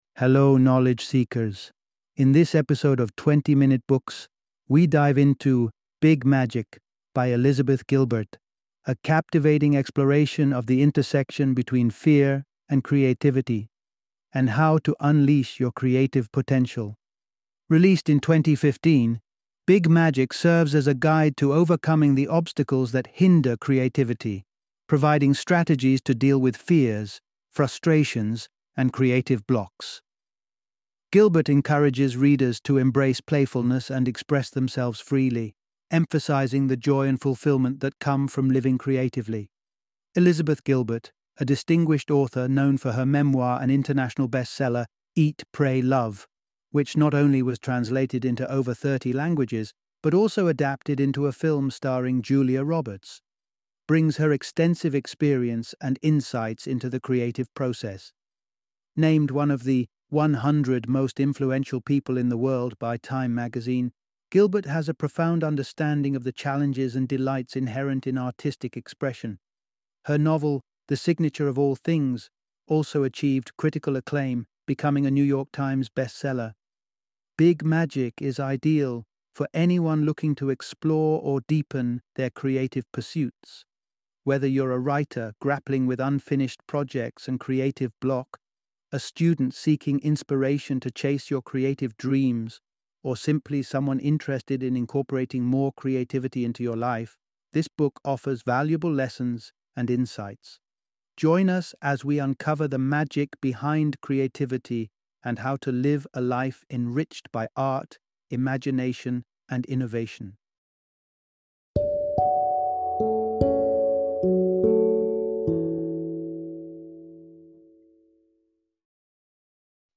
Big Magic - Audiobook Summary